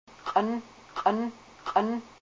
a native speaker of the Kasaan dialect of Alaskan Haida.